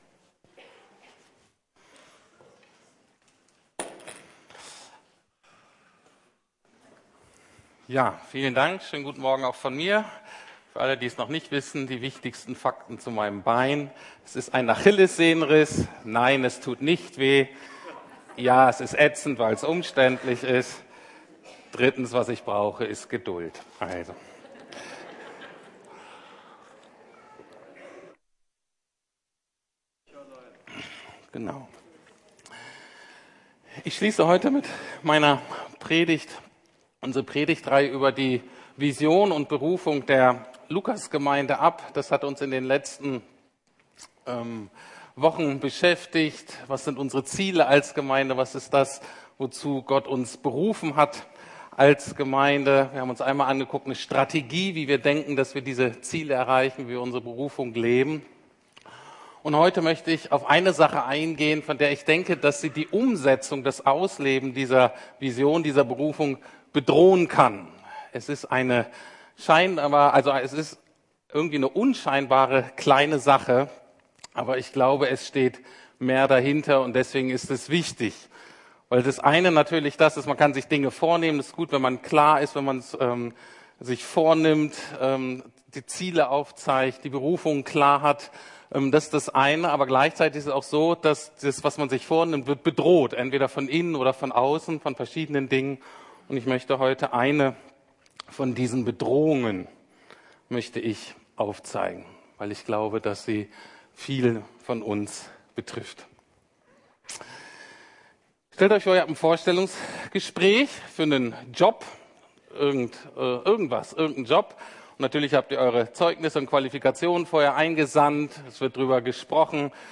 Das Erbe der Zukunft - Teil 7 ~ Predigten der LUKAS GEMEINDE Podcast